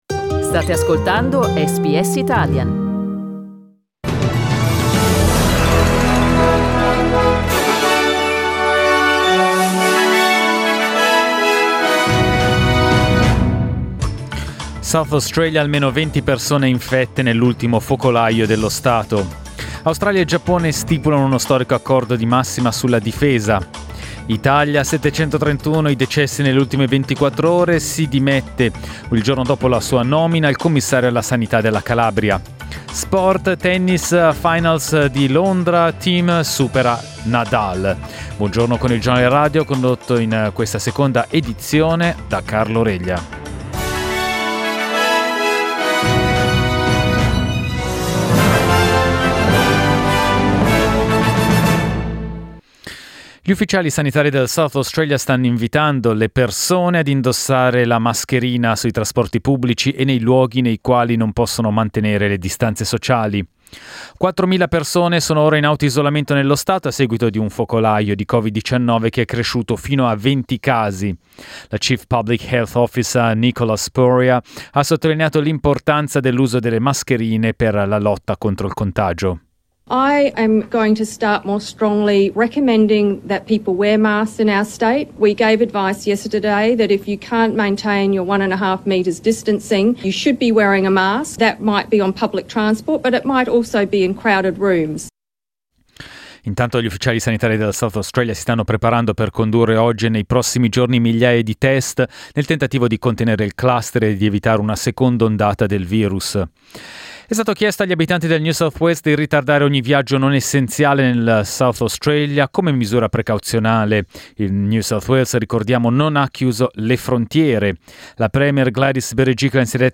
Our news bulletin in Italian.